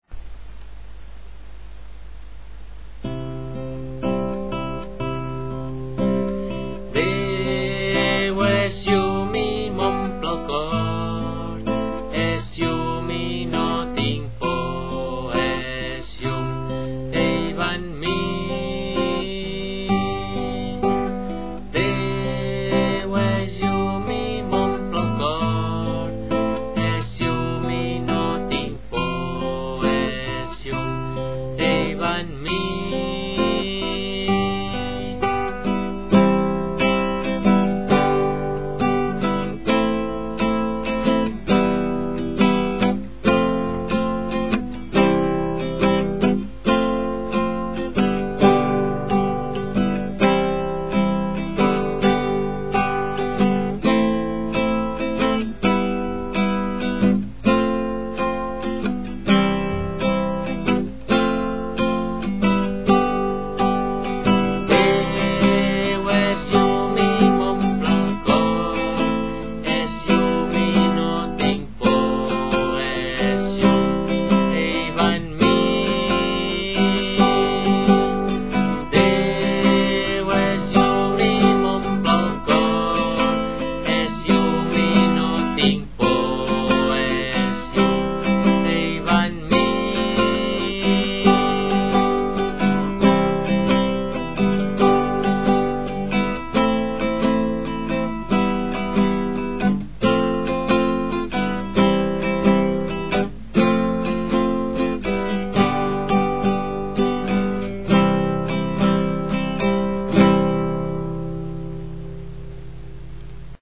a la flauta travessera
guitarra i veu.
i formar el grup de guitarra i flauta del Convent d’Arenys.